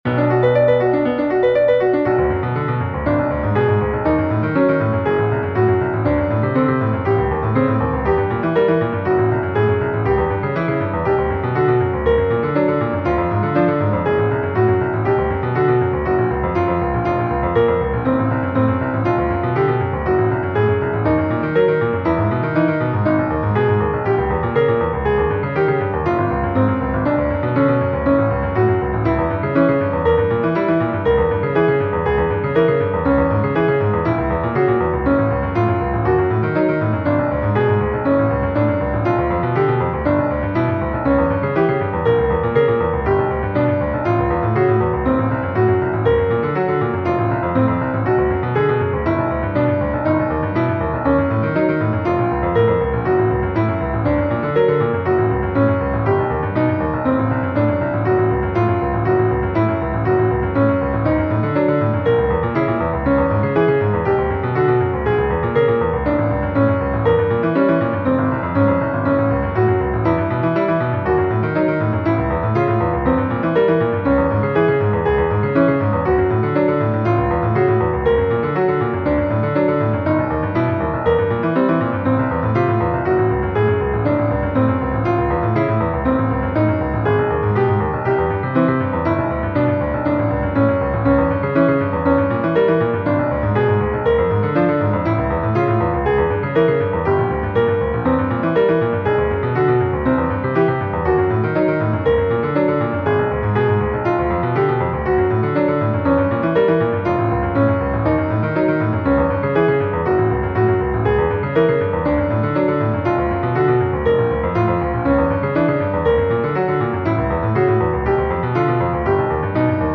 제 곡 타우송은 타우를 소수점 아래 296자리까지 숫자를 음에 대응시켰습니다.
0=C
1=C#
9=A#